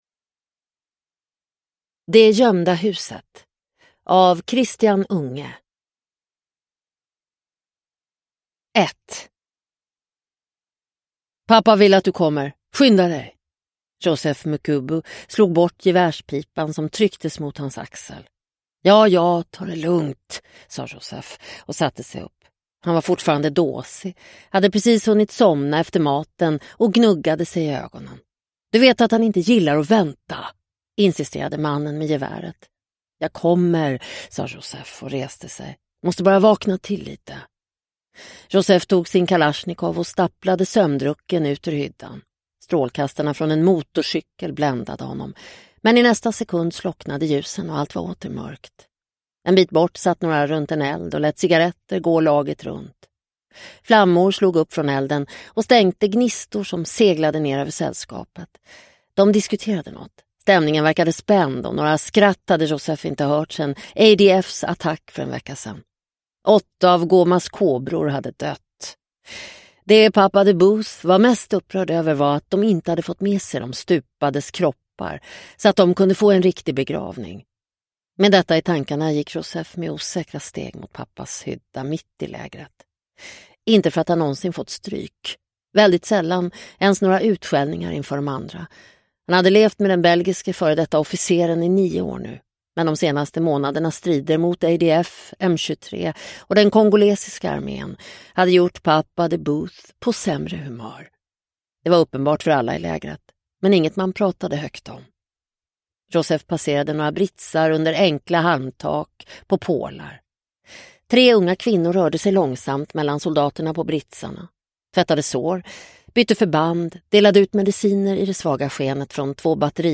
Det gömda huset – Ljudbok – Laddas ner